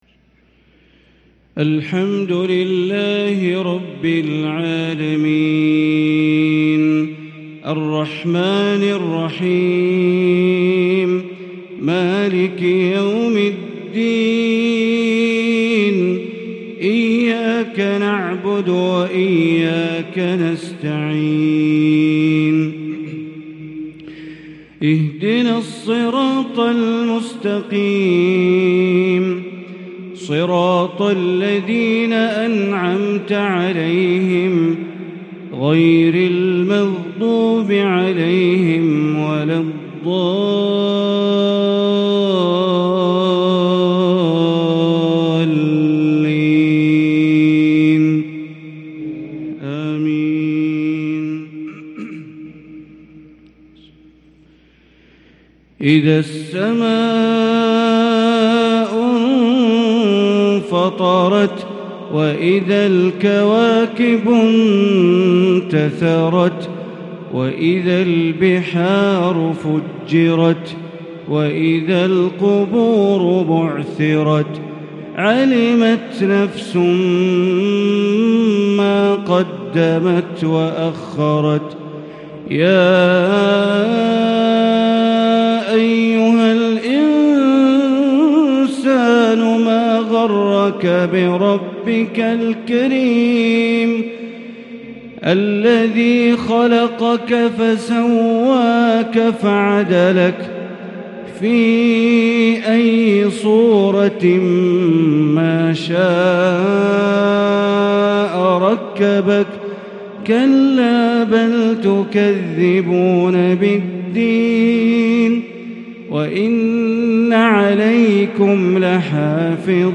عشاء الجمعة 7 محرم 1444هـ سورة الإنفطار | Isha prayer from surat al-Infitar 5-8-2022 > 1444 🕋 > الفروض - تلاوات الحرمين